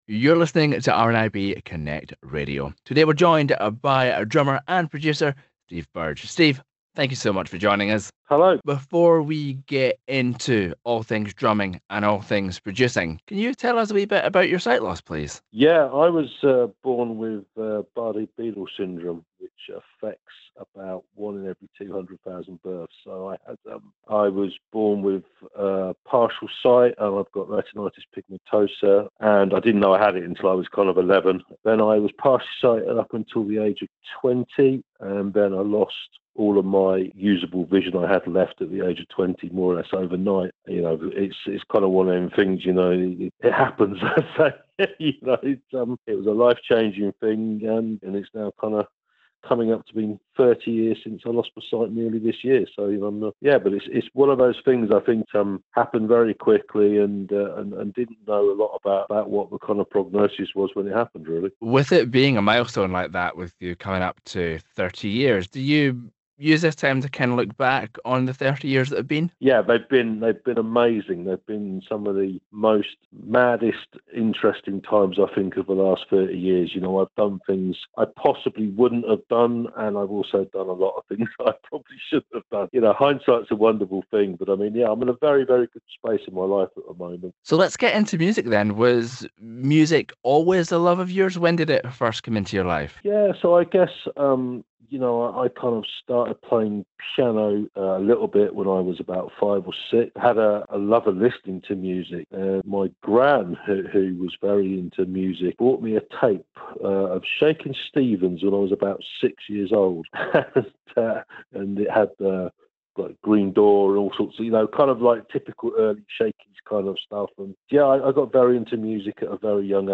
Music chat